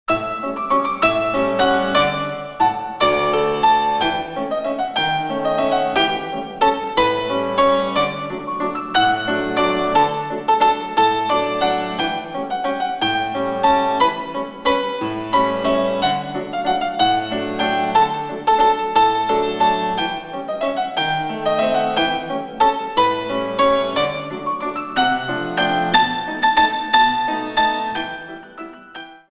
128 Counts